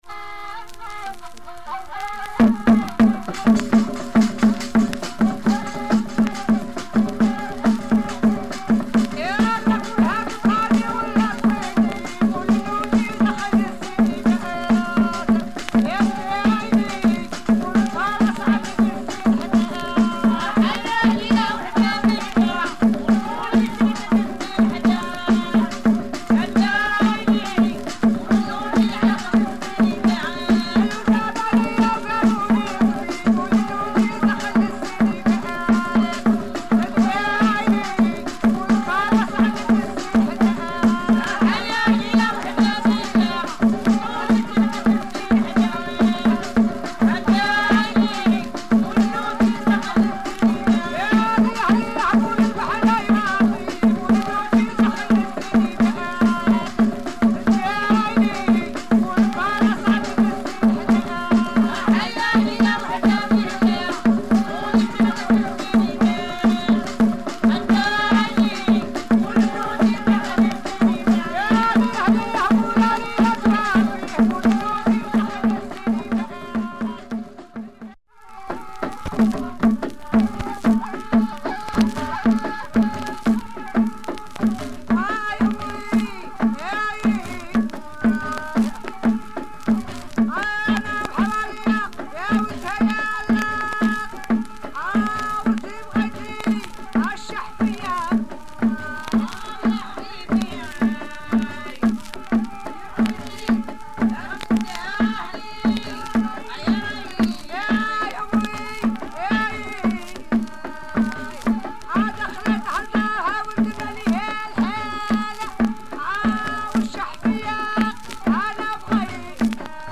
Raw Algerian female choir.